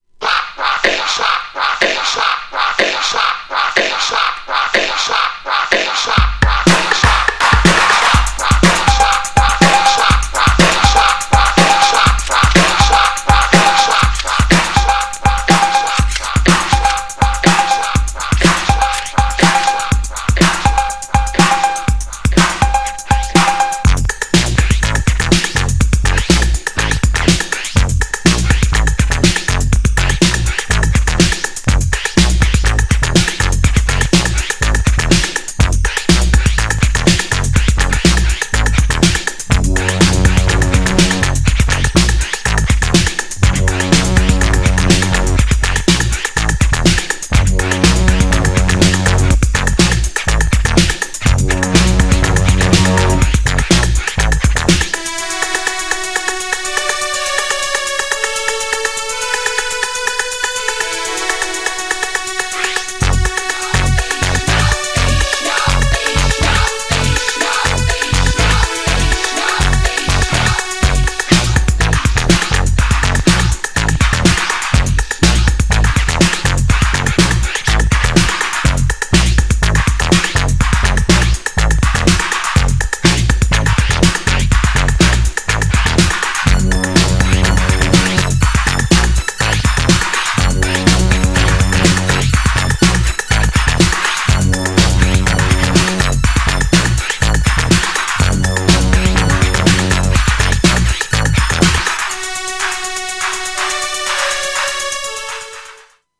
エレクトロクラシック!! ボーカルフレーズがよくネタにもされ、スクラッチネタとしても定番!!
GENRE Hip Hop
BPM 121〜125BPM